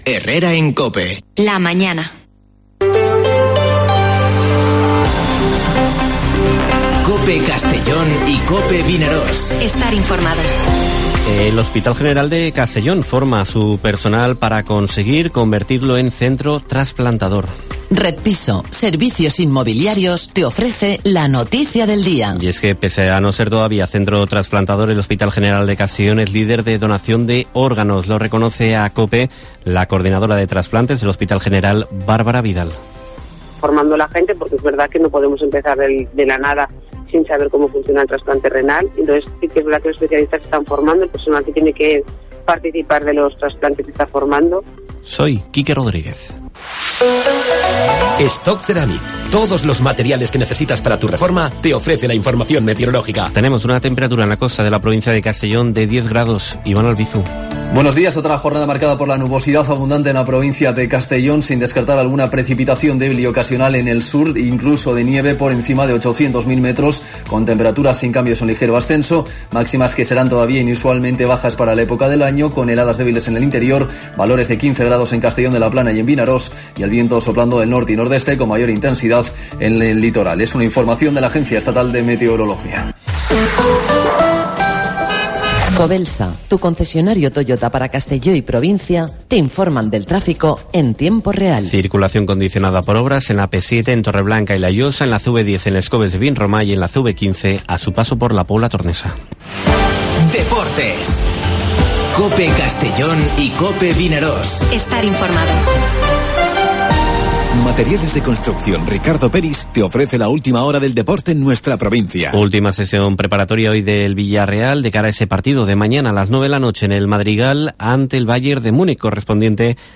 Informativo Herrera en COPE en la provincia de Castellón (05/04/2022)